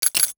NOTIFICATION_Glass_10_mono.wav